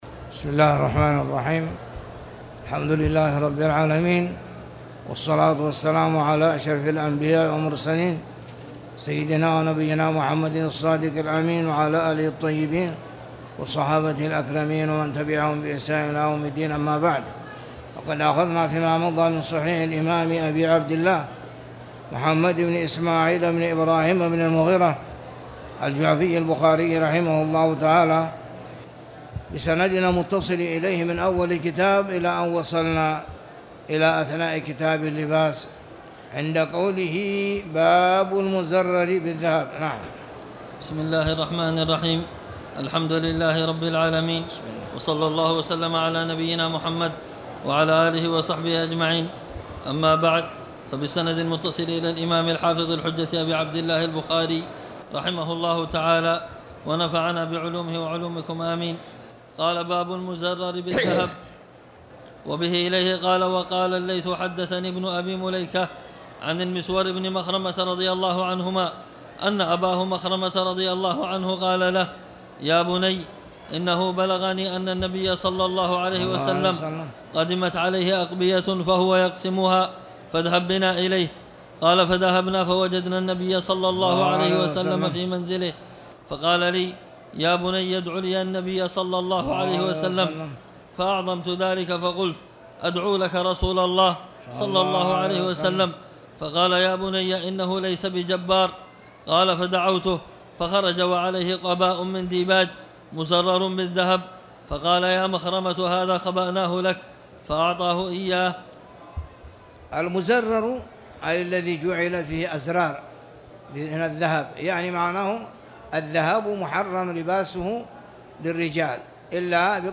تاريخ النشر ١ جمادى الأولى ١٤٣٨ هـ المكان: المسجد الحرام الشيخ